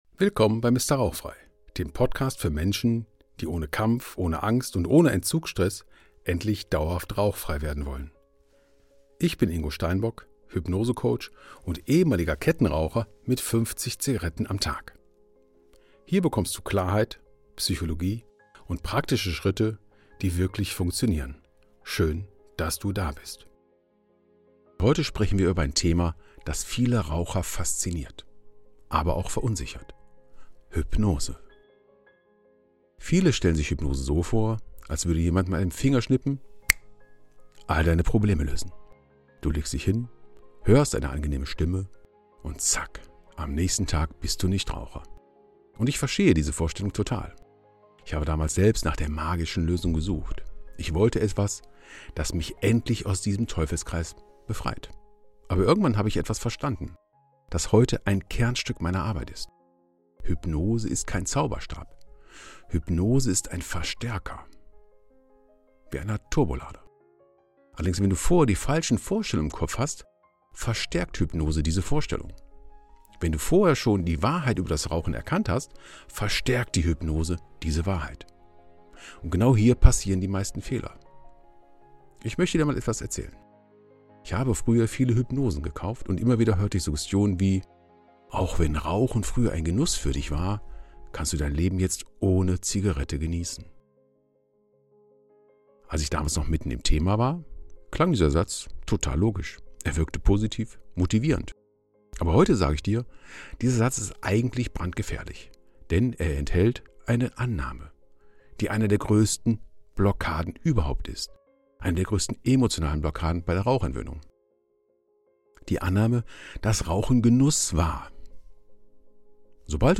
Musik lizenziert über AudioJungle (Envato Market).